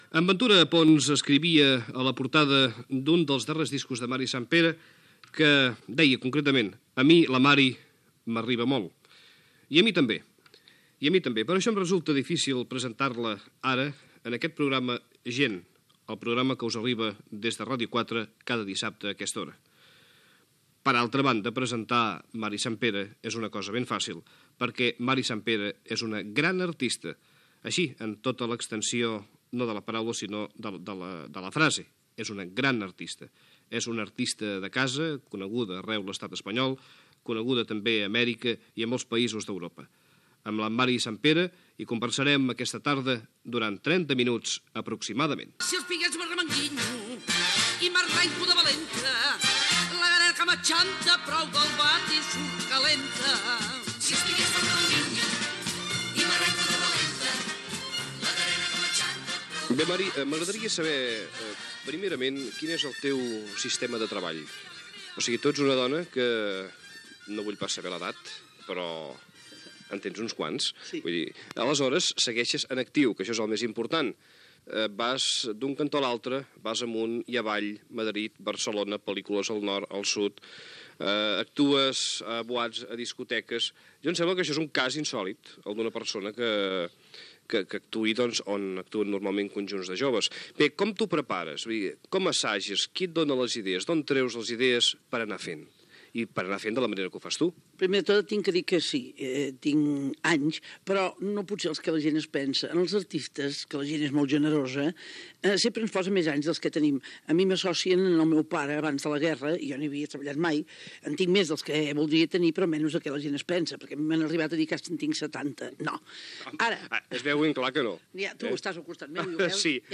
Presentació, tema musical, entrevista a l'actriu Mary Santpere. S'hi parla del seu mètode de treball, dels seus inicis, de la dona en els espectacles còmics, del públic jove que la va a veure
Entreteniment